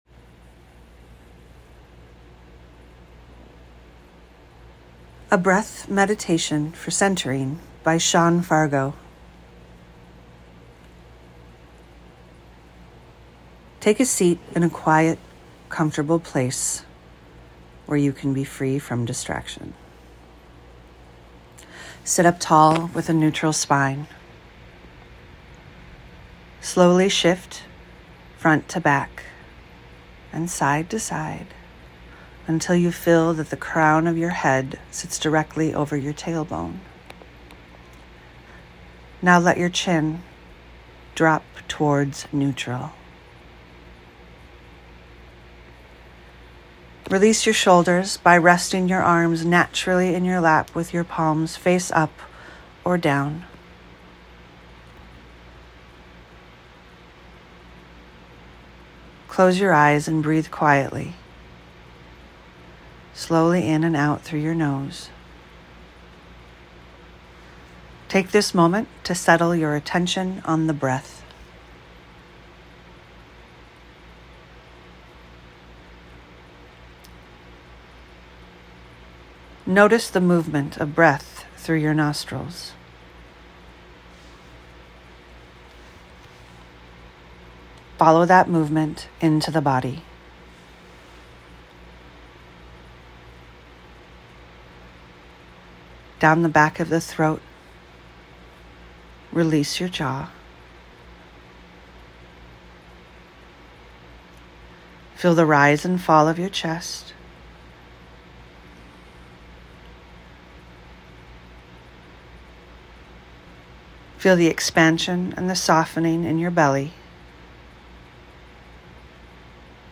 Anchor your body with a breath meditation for centering.